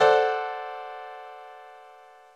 Терцквартакорд Це зображення не захищається авторським правом , тому що є тривіальним, не містить художньої цінності, складається тільки із загальновідомих елементів, що не мають автора.